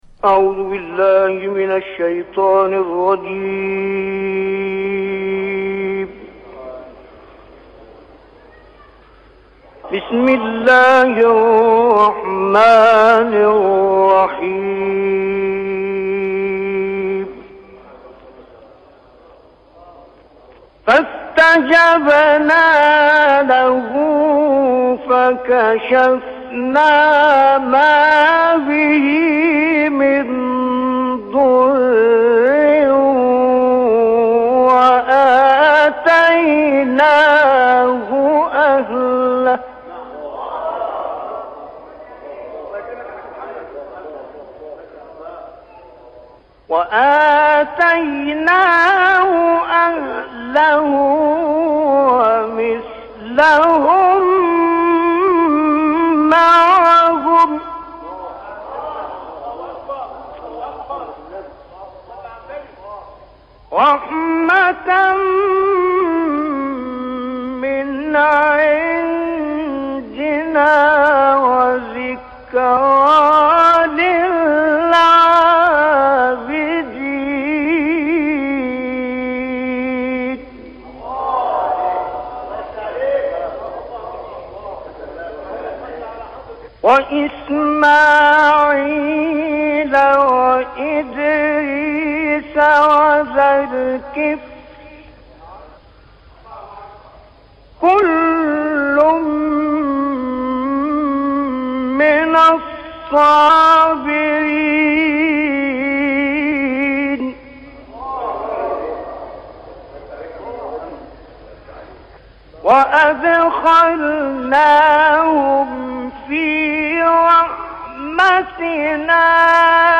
وی اظهار کرد: در ادامه، تکرار همین فراز در مقام رست و از ابتدای آیه تا کلمه «فَنَادَى» ادامه دارد و اوج این فراز نیز به زیبایی تا «سُبْحَانَكَ» انجام می‌شود که کاملا در مقام رست اجرا شده و قابل شنیدن است.
سپس، وی ادامه قرائت را تا پایان در مقام چهارگاه می‌خواند.